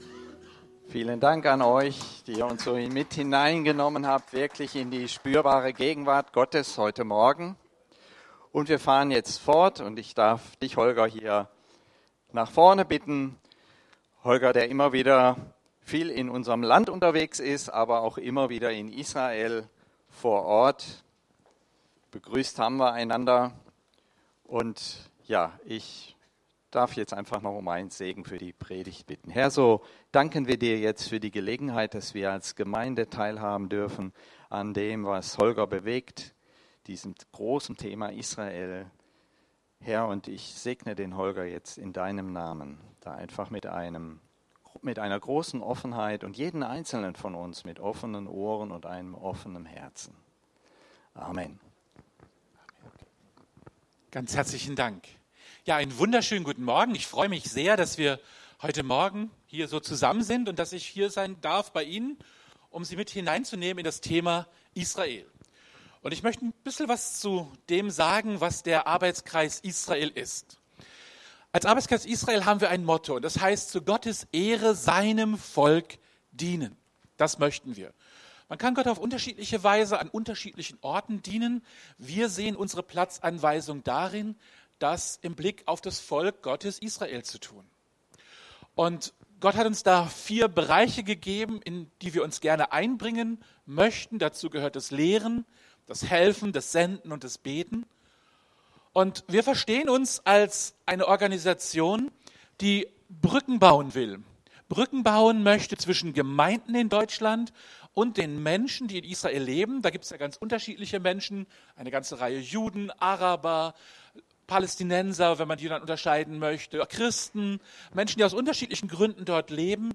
Eine Predigt